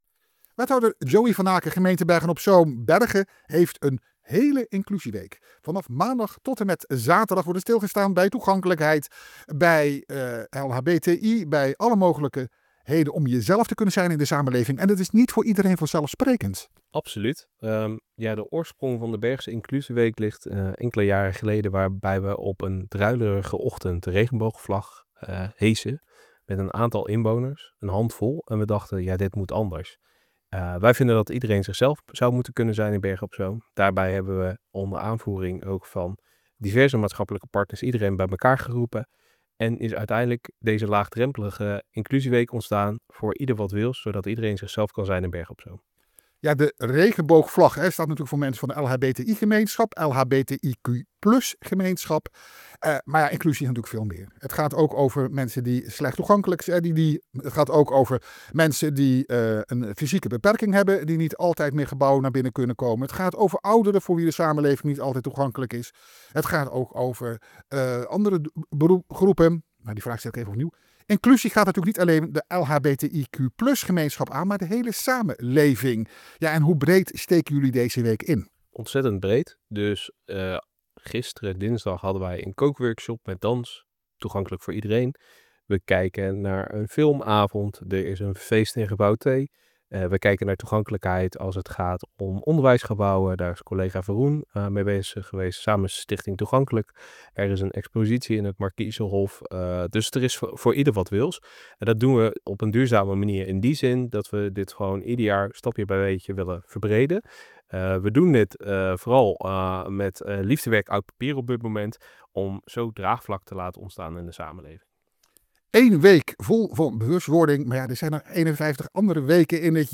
Audio: Wethouder Joey van Aken, over de Bergse inclusieweek van 6 tot en met 12 oktober.